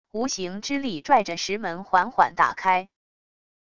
无形之力拽着石门缓缓打开wav音频